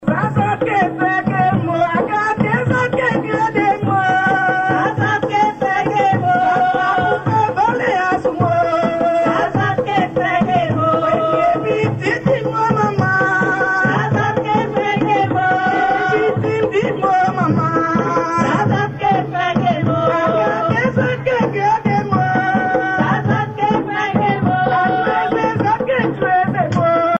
groupe folklorique Dahlia
danse : grajé (créole)
Pièce musicale inédite